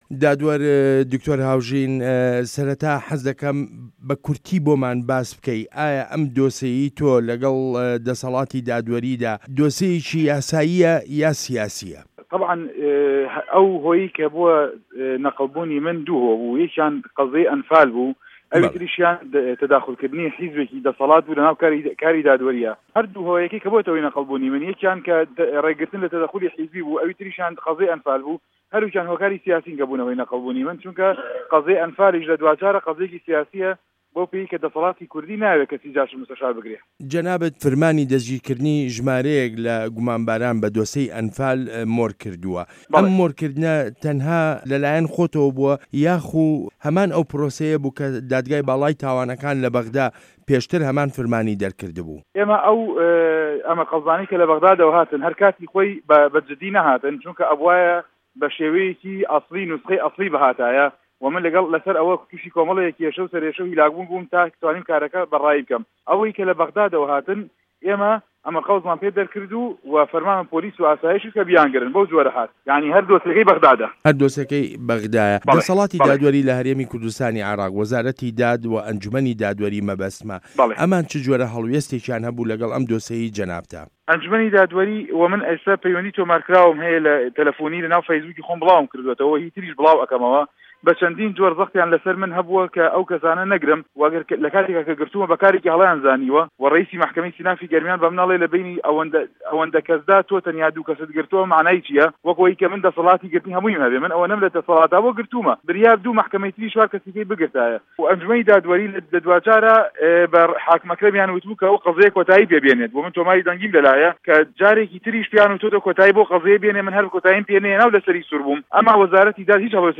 وتووێژ له‌گه‌ڵ دادوه‌ر دکتۆر هاوژین حامد